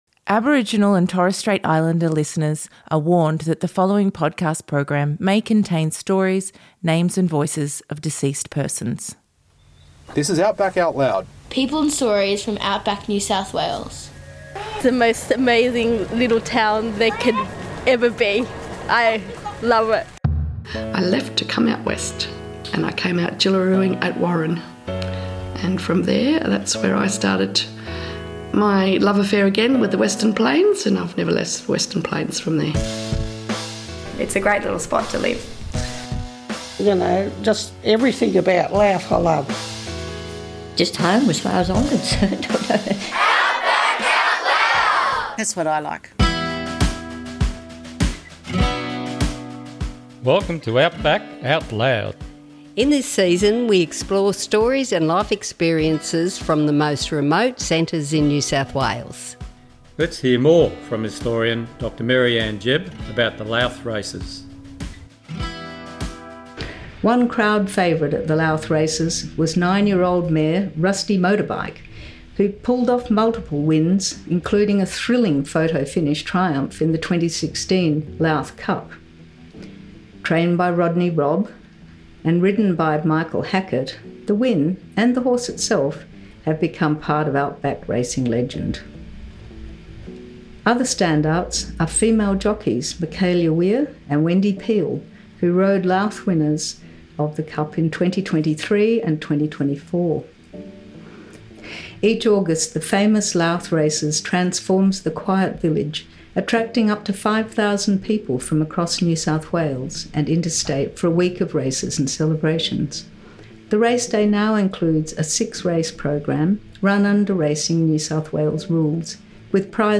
Recorded on Ngemba Country In this episode, we head to Louth, NSW, a tiny town on the banks of the Darling River that comes alive every August for one of Australia’s most iconic outback events — the Louth Races.